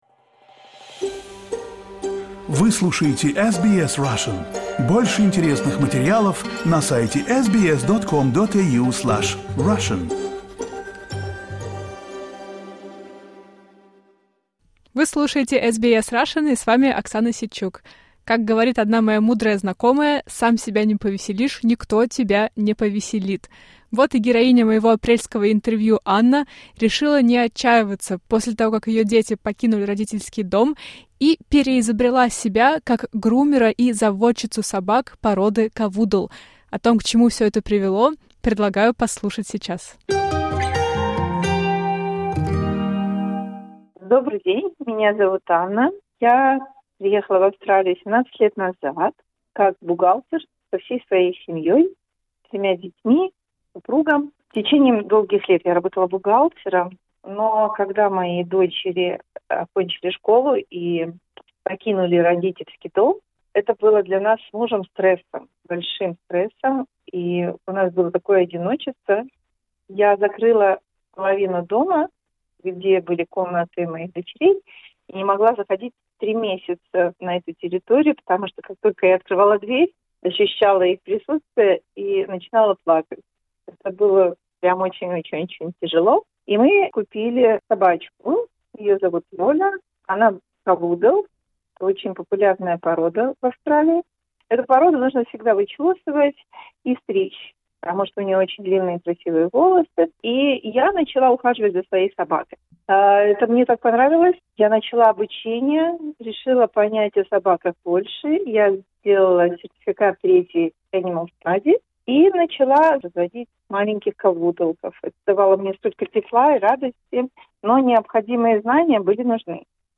Вспоминаем любимые интервью уходящего года.